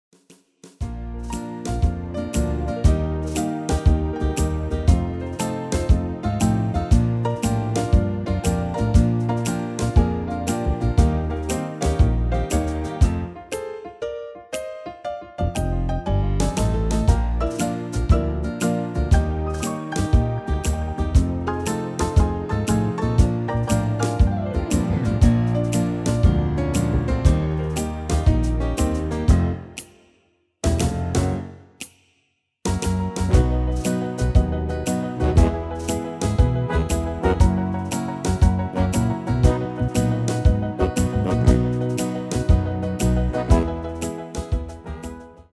Genre: Country & Western
- Géén vocal harmony tracks
Demo's zijn eigen opnames van onze digitale arrangementen.